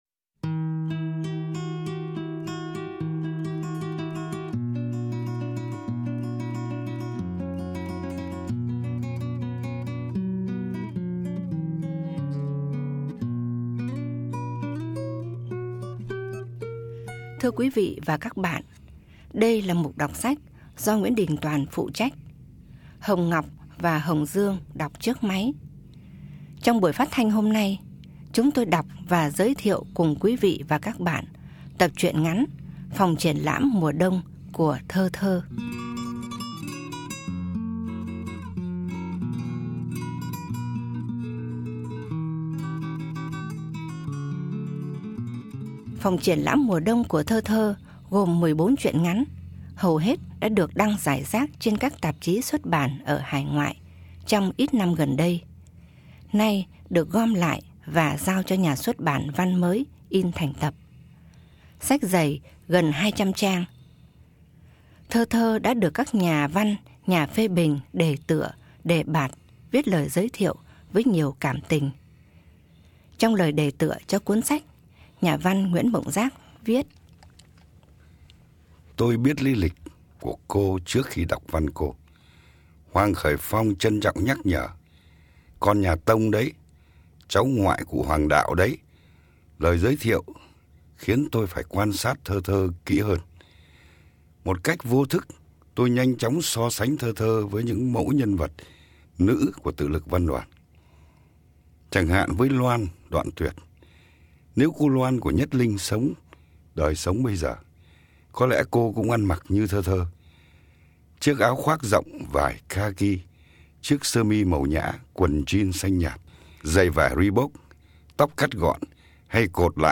Biên sọan: Nguyễn Đình Tòan
Giọng đọc: